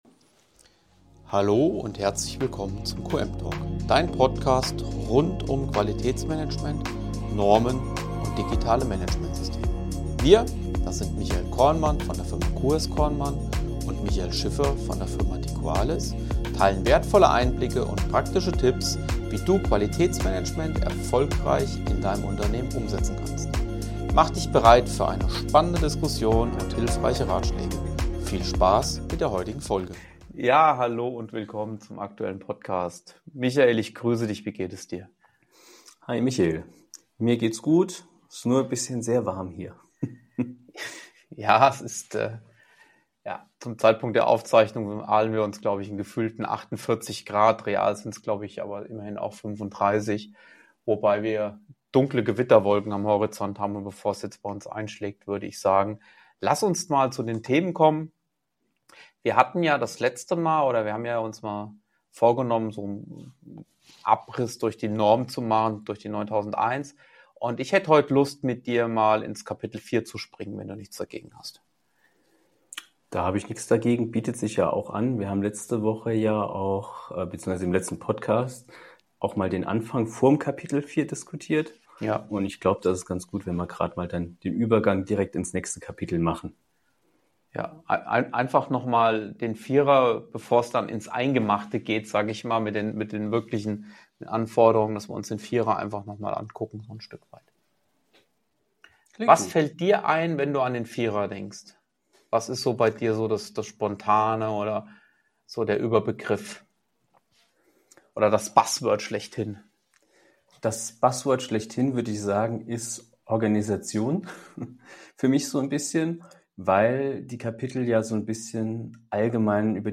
Locker, praxisnah und mit Beispielen aus der Beratungspraxis geben sie konkrete Impulse für den Start ins Qualitätsmanagement – ganz ohne Norm-Kauderwelsch.